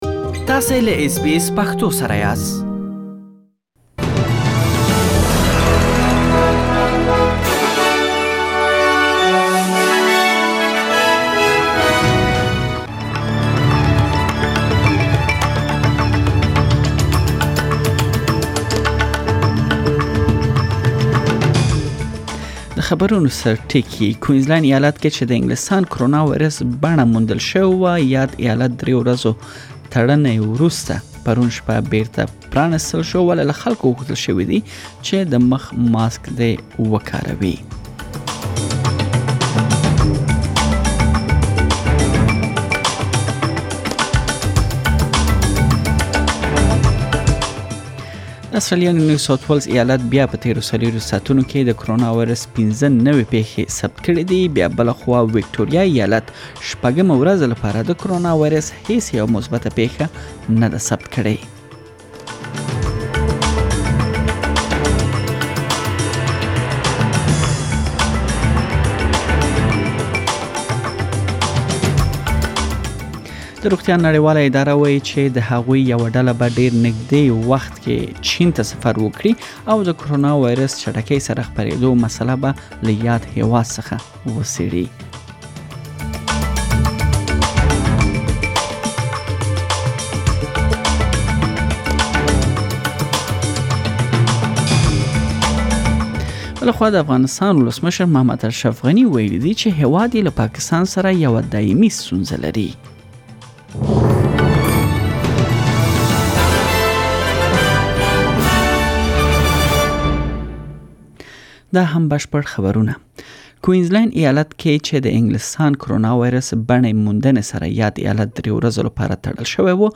د نن ورځې مهم خبرونه